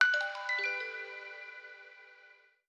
SFX_Menu_Confirmation_03.wav